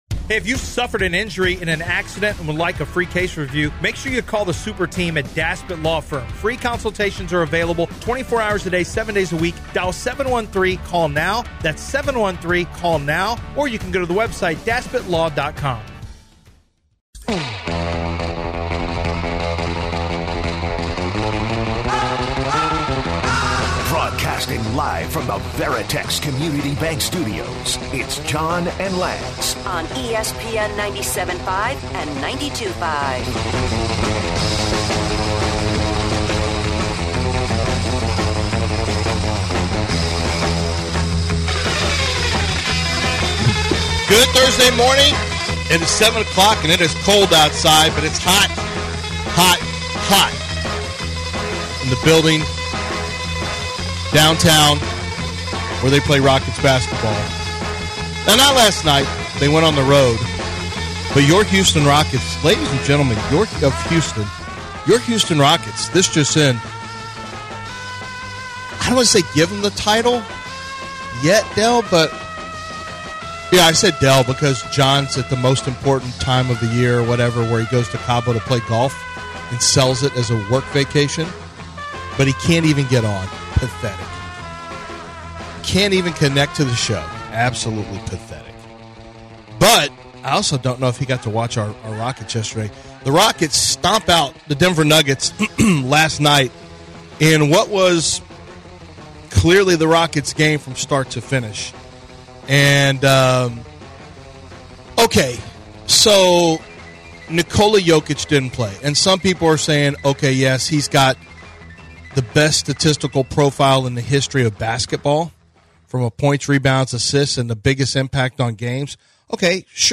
1-16 Hour 1: Live from the Cabo Cup & The Rockets win big in Denver!